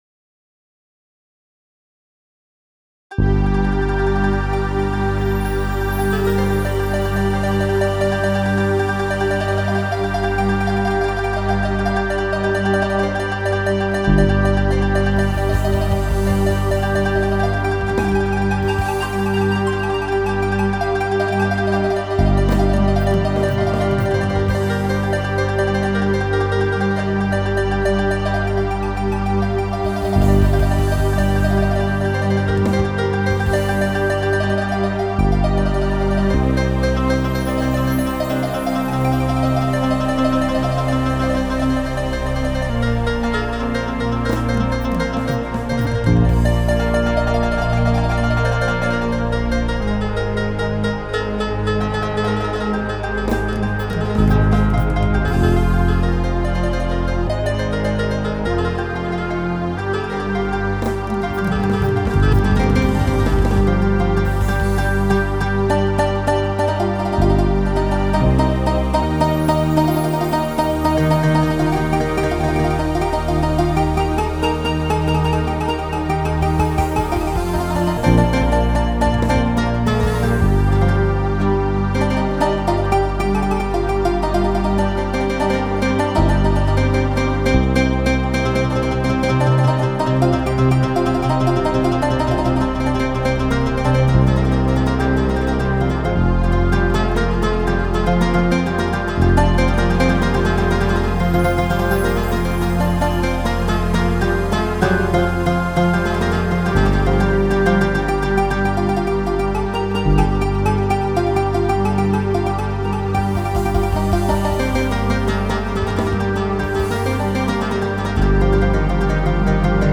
סט מזרחי לימאהה